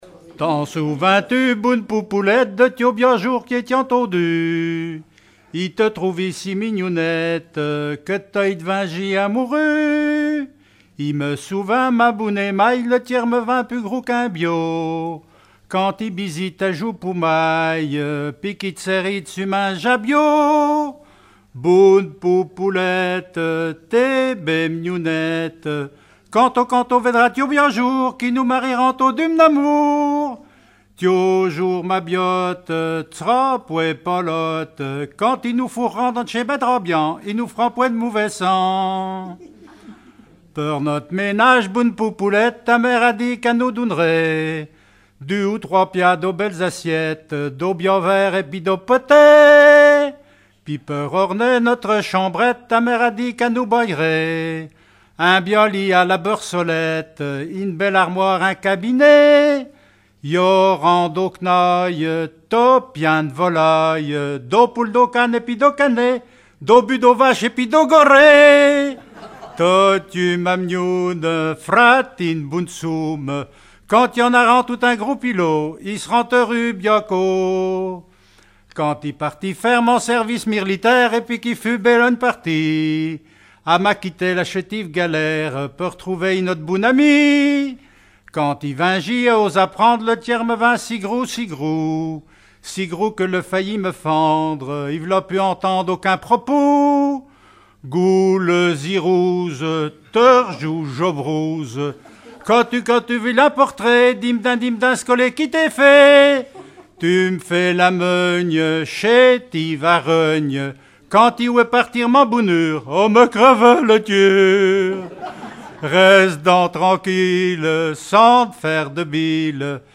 Mémoires et Patrimoines vivants - RaddO est une base de données d'archives iconographiques et sonores.
Regroupement de chanteurs du canton
Pièce musicale inédite